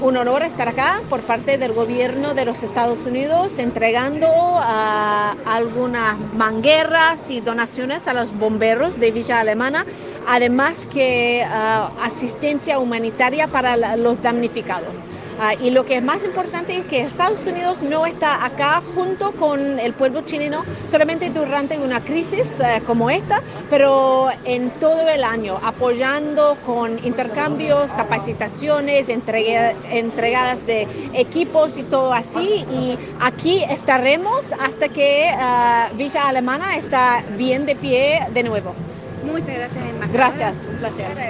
Con una ceremonia celebrada en el cuartel de calle Arrieta, entre la embajada de Estados Unidos, el Cuerpo de Bomberos y la Municipalidad de Villa Alemana, se dio a conocer la entrega de 50 mangueras donadas por la Agencia estadounidense para el Desarrollo Internacional (USAID), a las cuatro compañías de la comuna.
La embajadora de EE. UU, Bernadette Meehan, expresó que